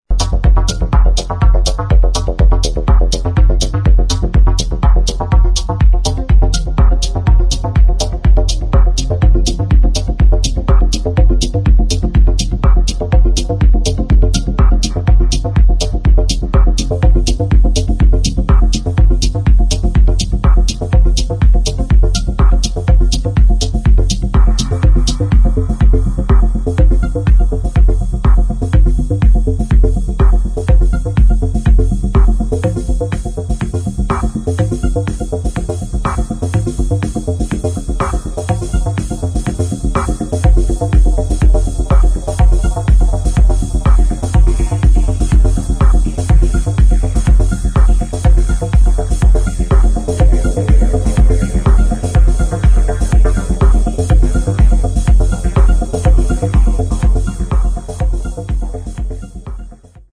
[ TECHNO ]